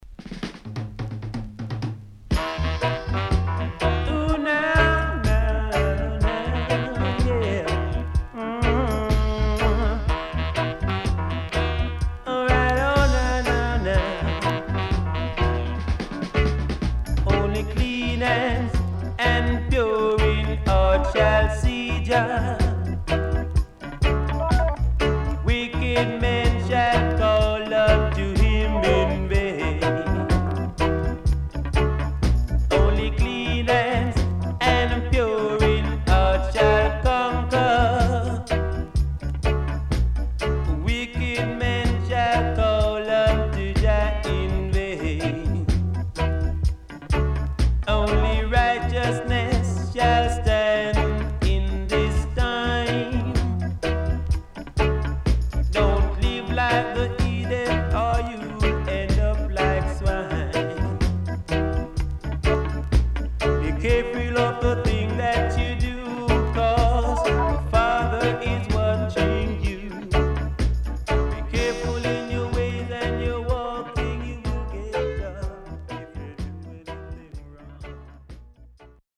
SIDE A:少しノイズ入ります。